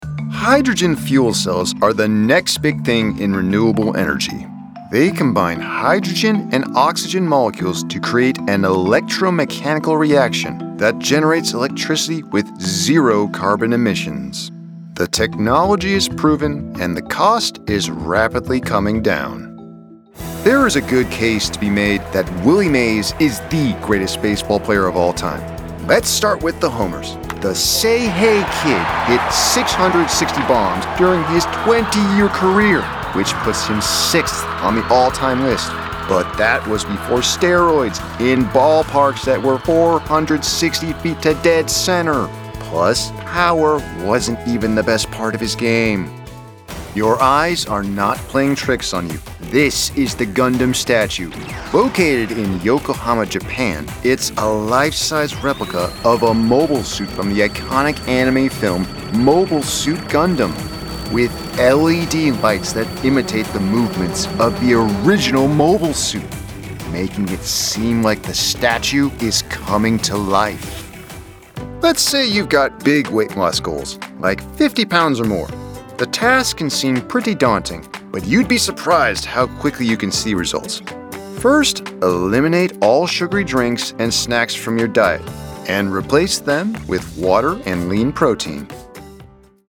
Narration Demo Reel
English - USA and Canada
Young Adult